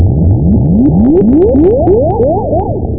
FlameAll.mp3